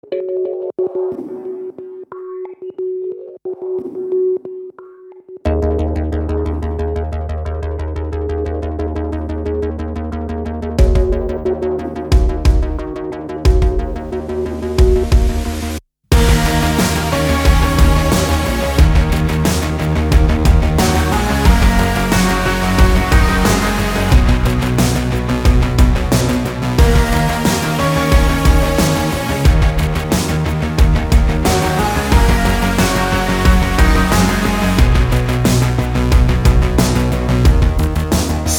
• Качество: 320, Stereo
dance
Electronic
без слов
нарастающие
Pop Rock
electro
progressive-pop-rock
рок